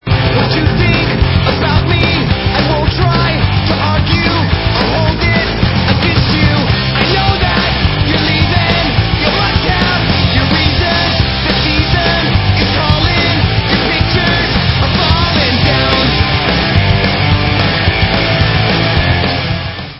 Rock/Punk